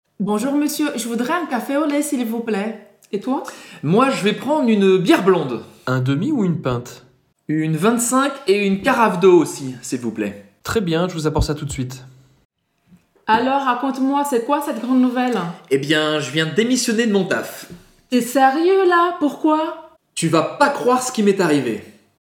Baixe o áudio lento